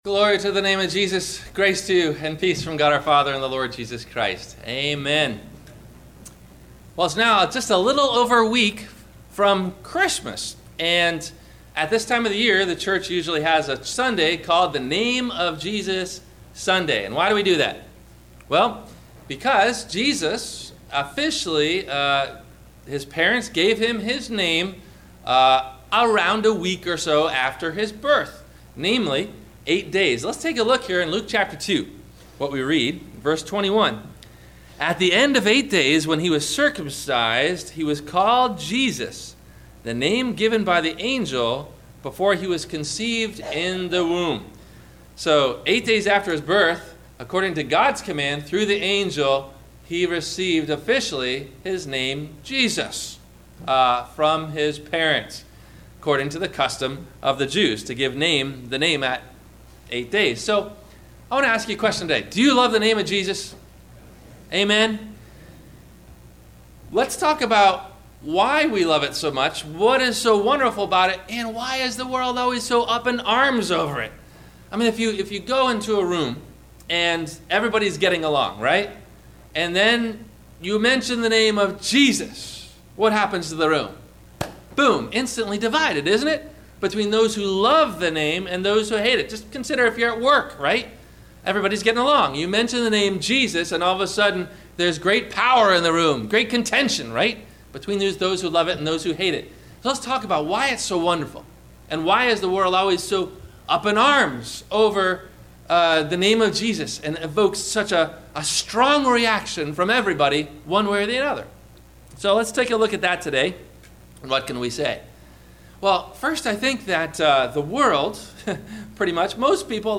The War Over the Name of Jesus - Sermon - January 03 2016 - Christ Lutheran Cape Canaveral